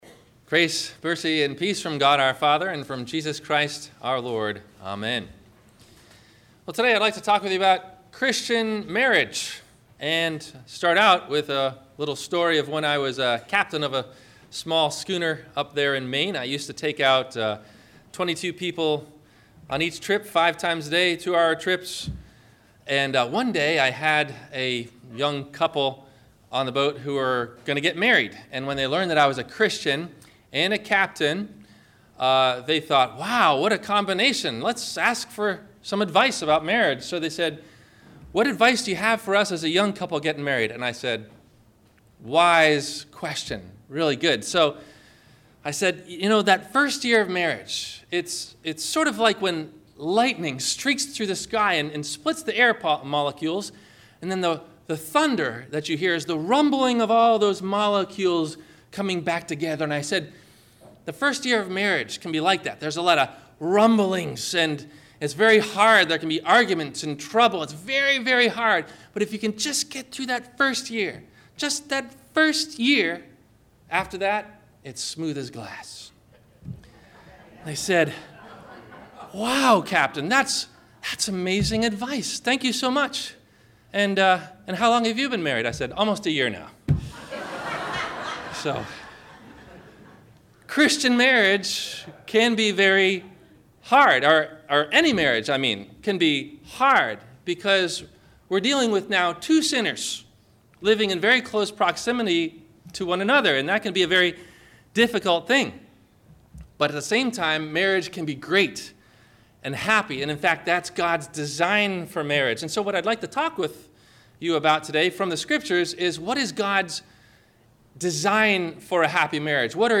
What is a Good Christian Marriage? - Sermon - October 02 2016 - Christ Lutheran Cape Canaveral